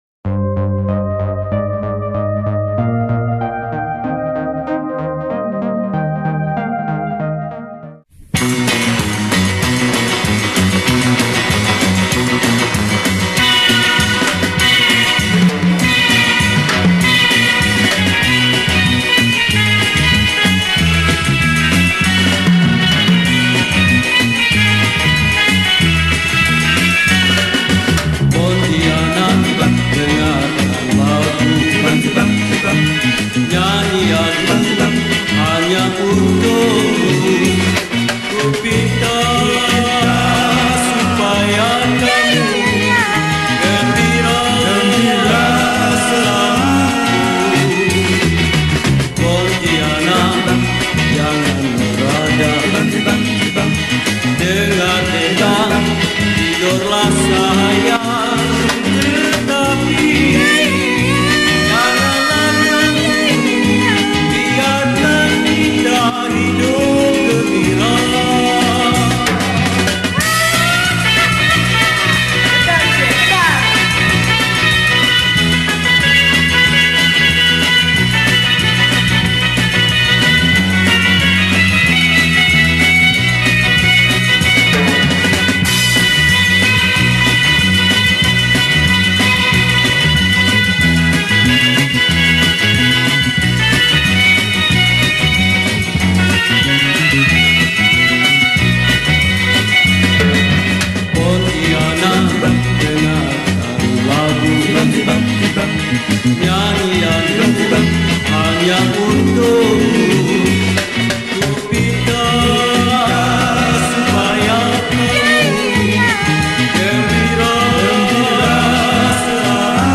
Pop Yeh Yeh
Malay Song